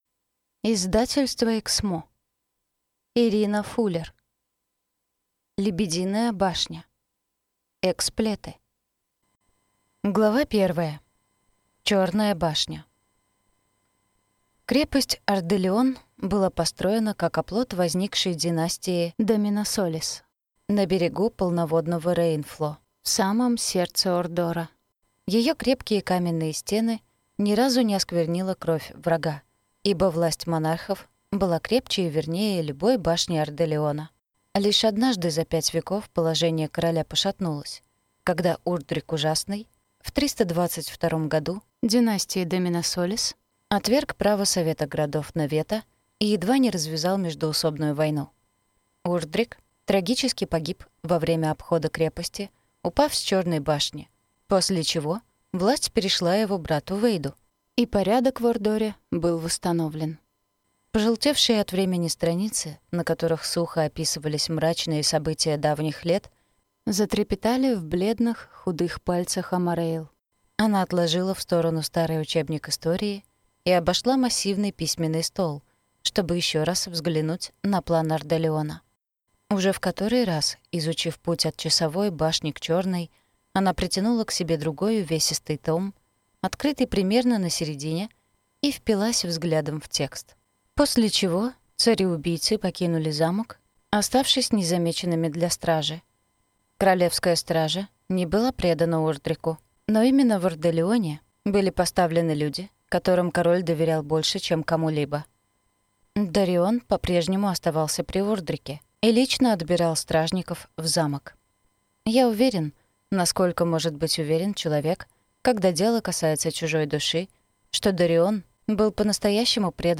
Аудиокнига Эксплеты. Лебединая башня | Библиотека аудиокниг